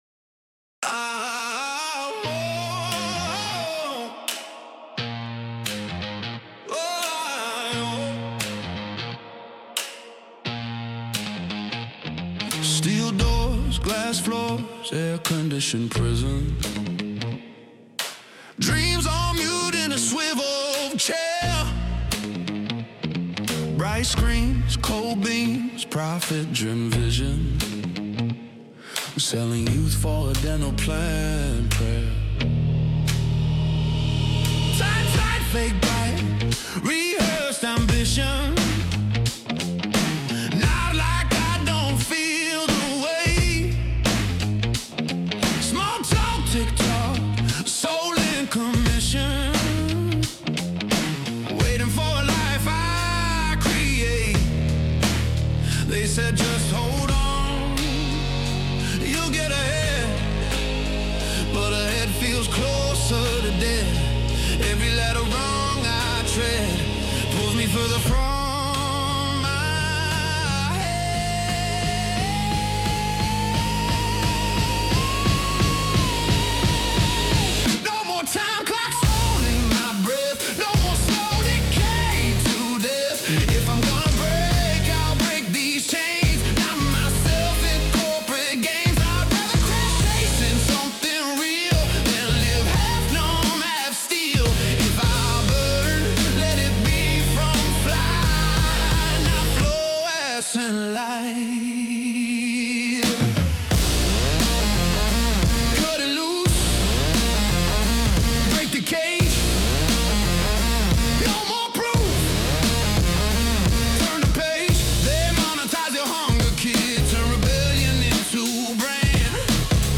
Official Studio Recording